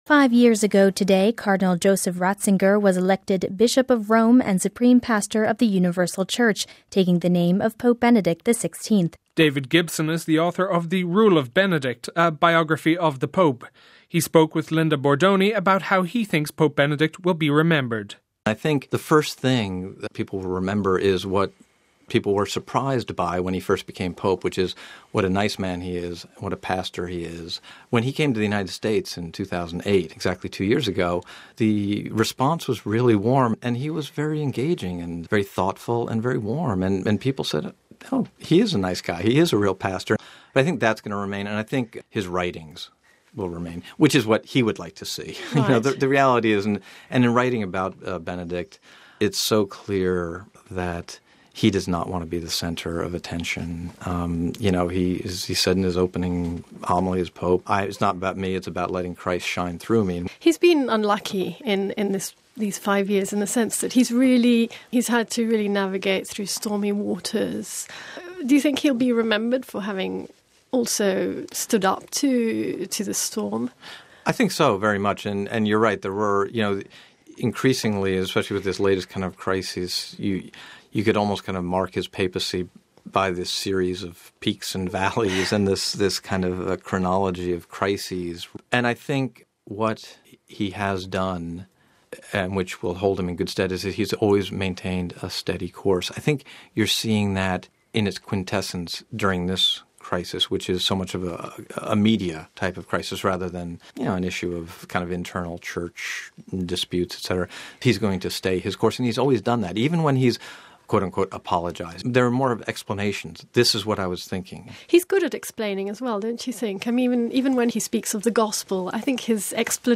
He spoke with us about how he thinks Pope Benedict will be remembered: